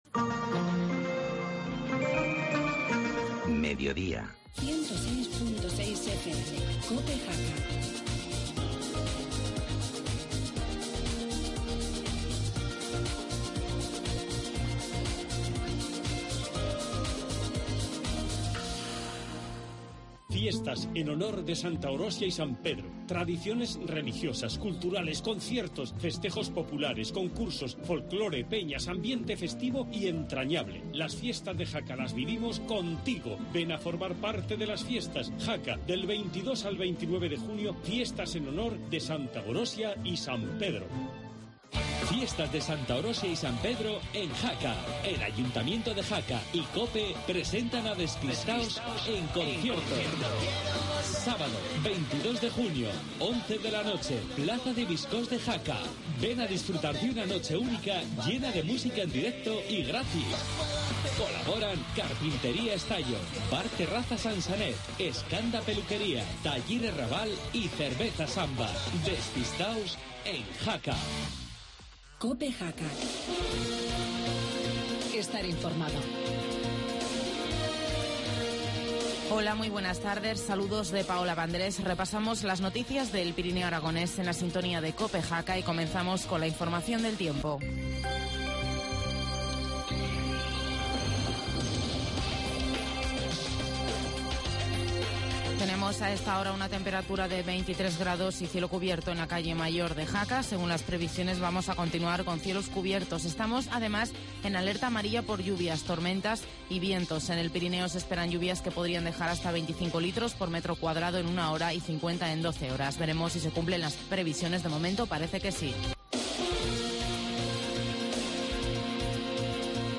Informativo mediodía, lunes 17 de junio